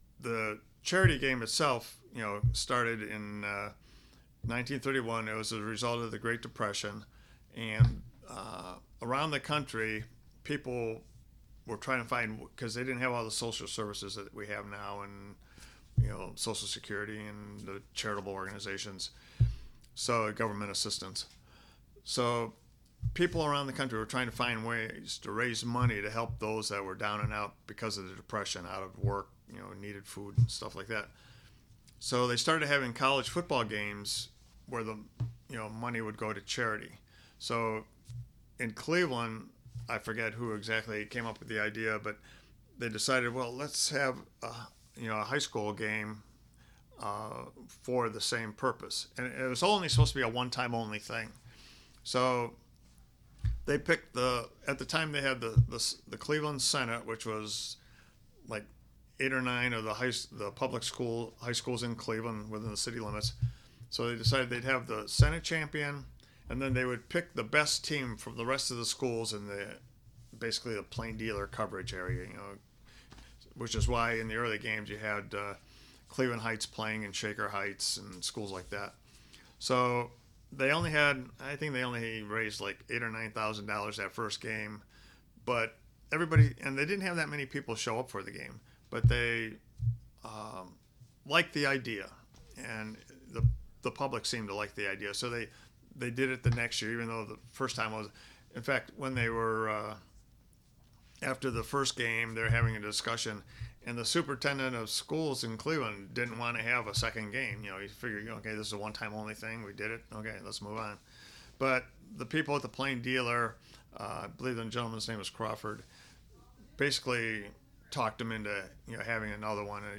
Cleveland Regional Oral History Collection.